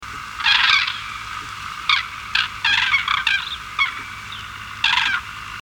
Grue cendrée
Grus grus
Les grues, qui trahissent leur présence par leurs cris claironnants,